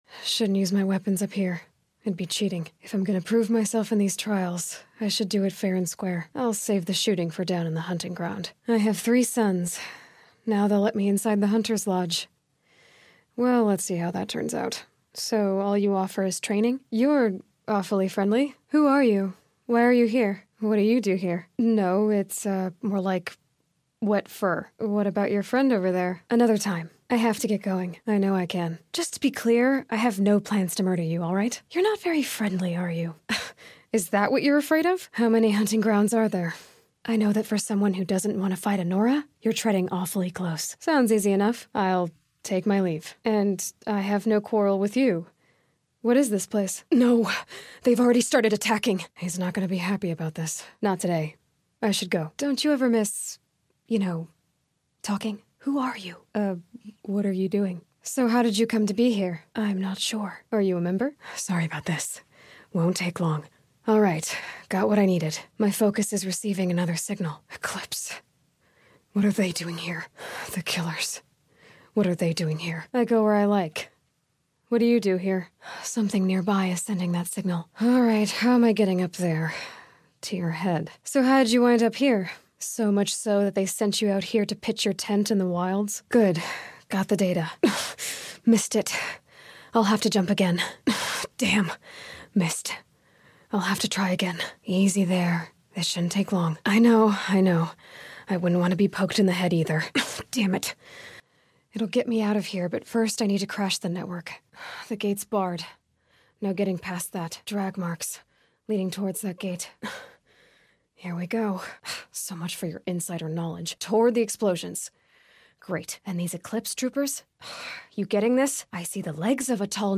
Could you try some clean voices such as
Meaning samples of the game audio were merged into one without padding.
aloy_original.mp3